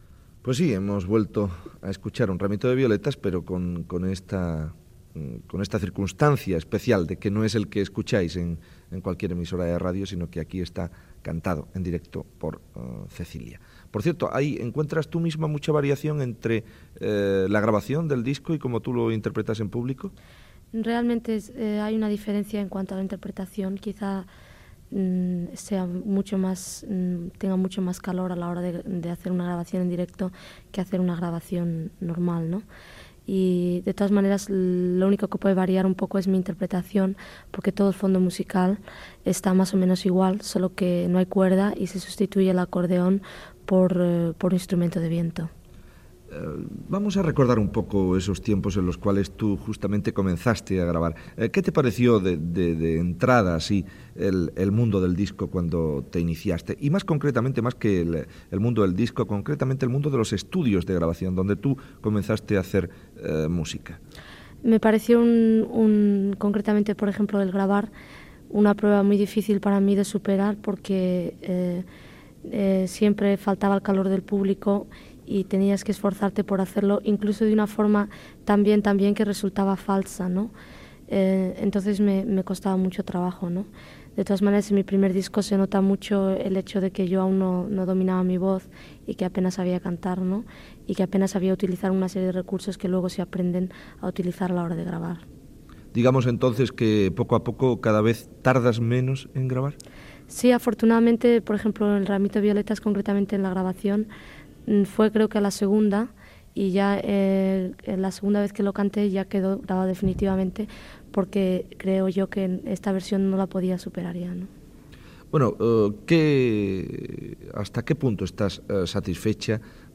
Entrevista a la cantant Cecilia (Evangelina Sobredo Galanes)